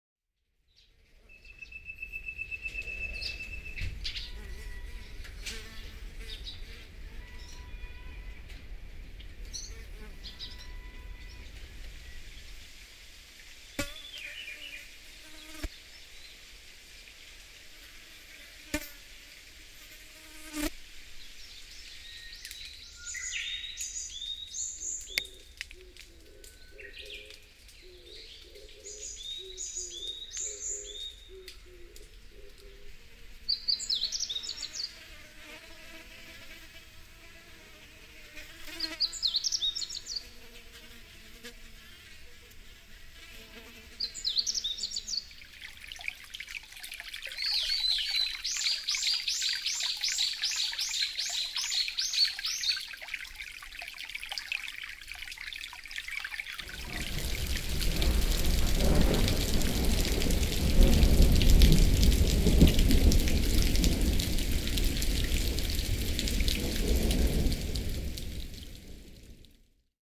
バイノーラル録音とは、簡単に言えばヘッドホン向けに処理された音声だそうです。
自然の音